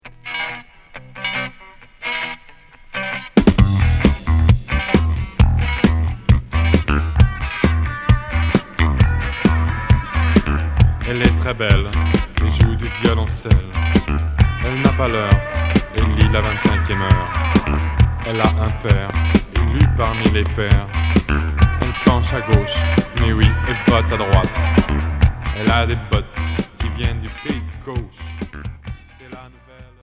Rock reggae d a da